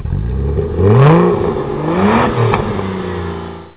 motore3-8bit.wav